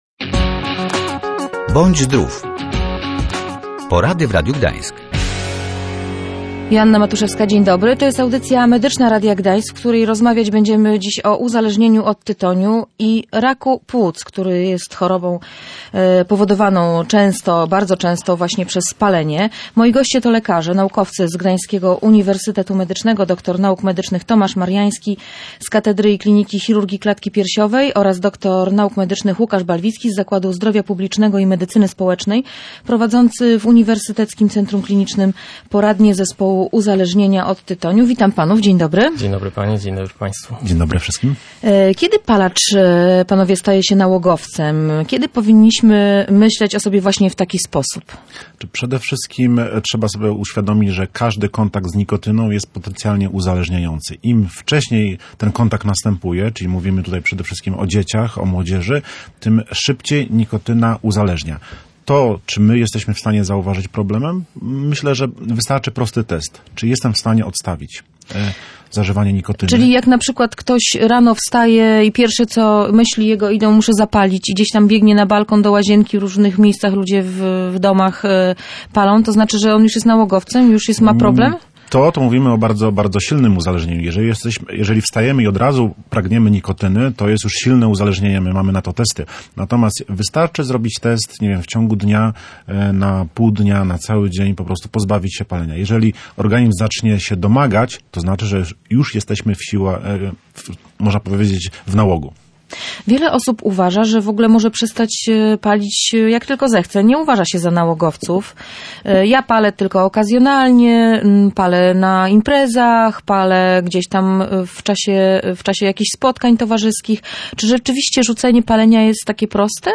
W audycji mowa była też o regeneracji płuc po rzuceniu palenia.